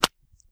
High Quality Footsteps
STEPS Pudle, Walk 25.wav